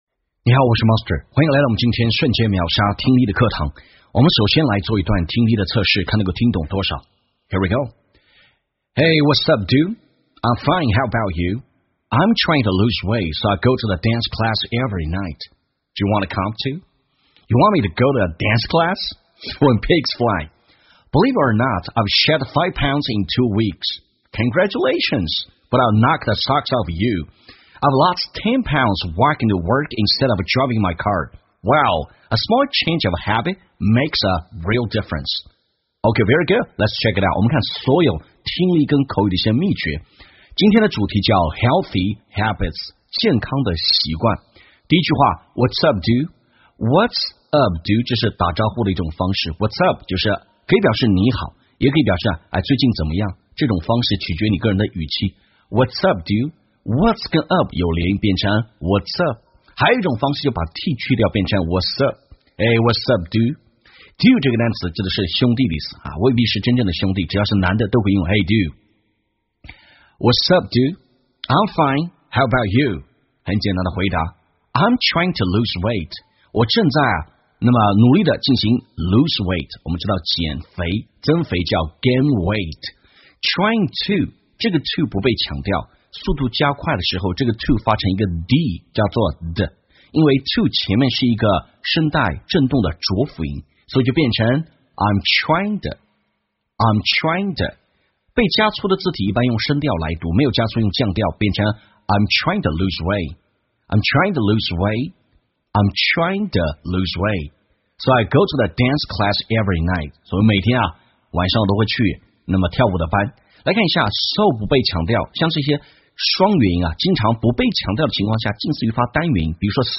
在线英语听力室瞬间秒杀听力 第657期:Healthy Habits 健康习惯的听力文件下载,栏目通过对几个小短句的断句停顿、语音语调连读分析，帮你掌握地道英语的发音特点，让你的朗读更流畅自然。